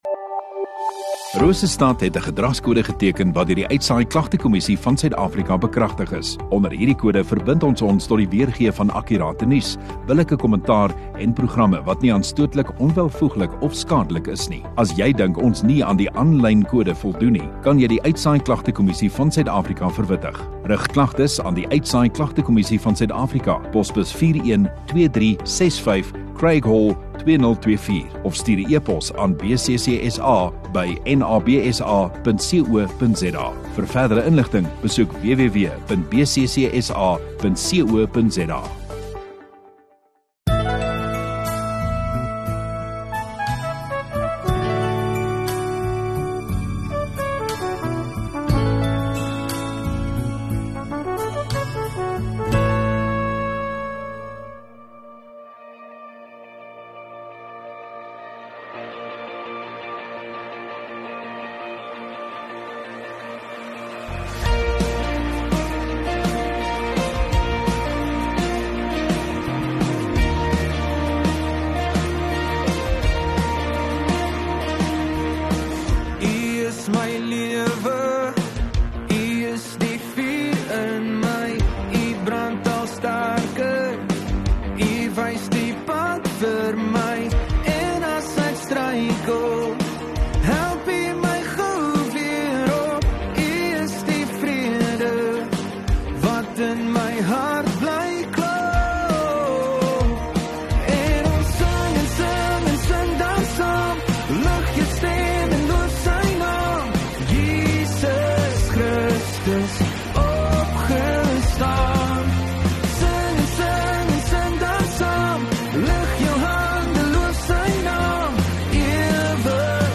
19 Dec Donderdag Oggenddiens